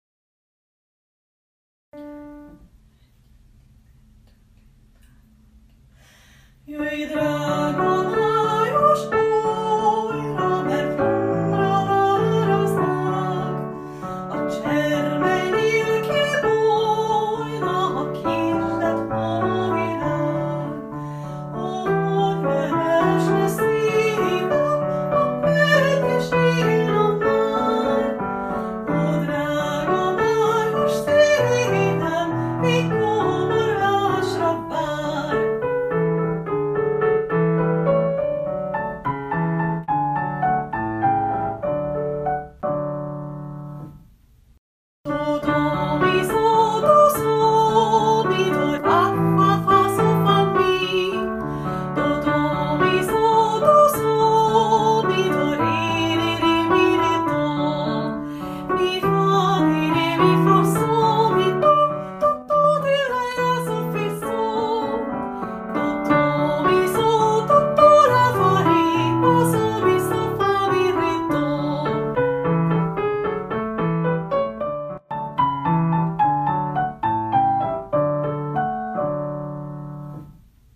A változatosság kedvéért a felvételek otthon készültek. Vigyázat, nyomokban speciális effekteket (zajokat) tartalmazhatnak.